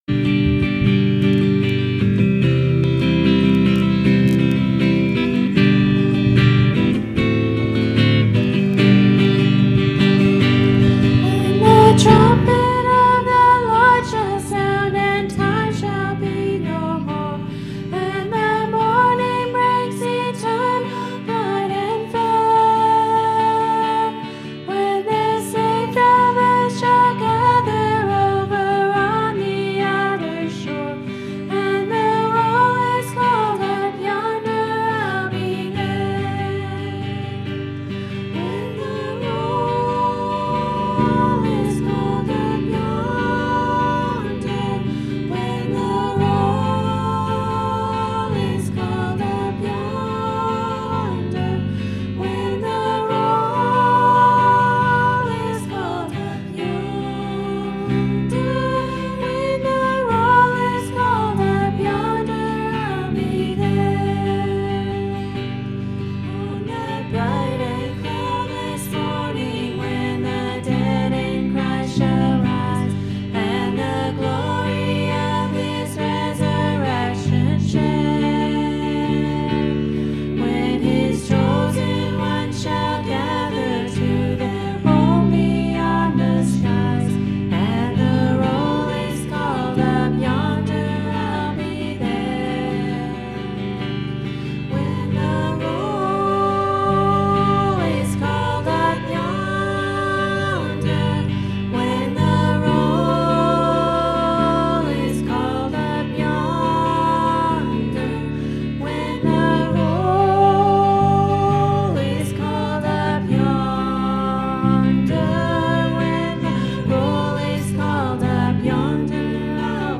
Service.mp3